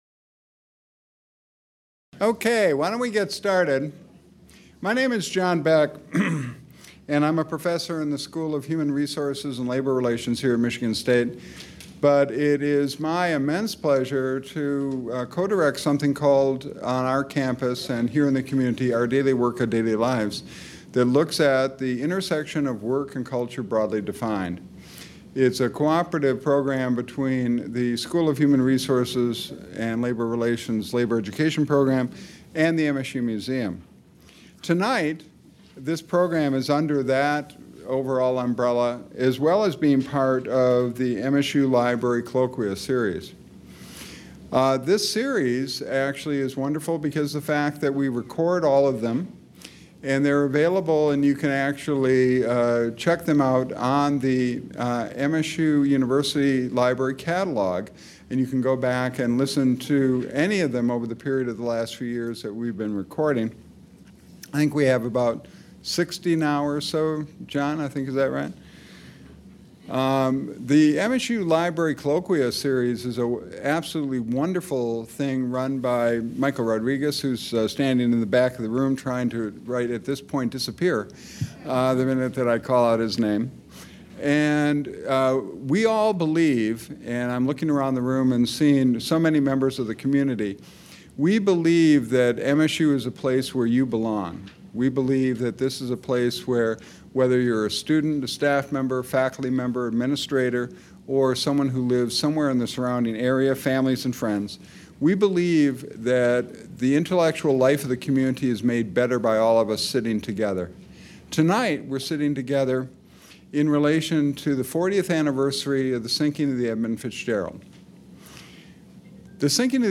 Panelists discuss the iron mining and shipping industries, Great Lakes shipwrecks, and commemorate the sinking of the ore ship Edmund Fitzgerald in Lake Superior on November 10, 1975. Panelists answer questions from the audience after the presentations.